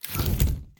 umbrella1.ogg